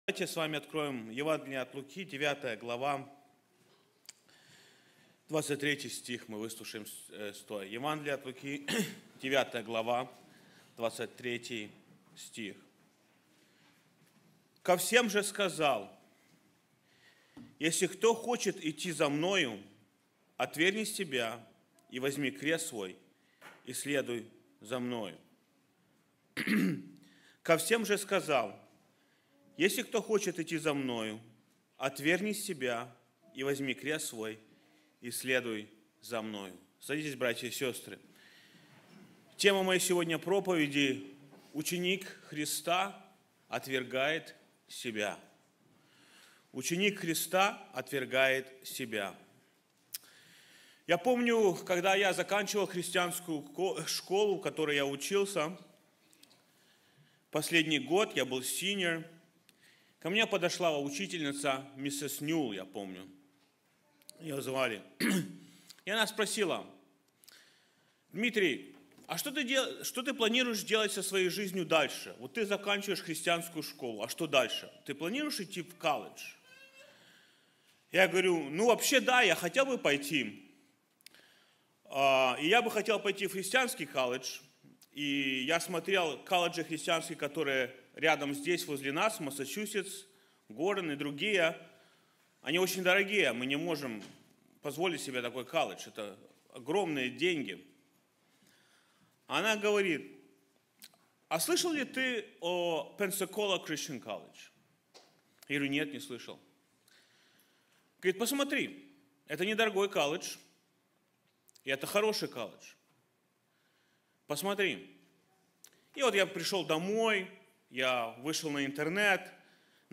sermon-disciple-denies-himself.mp3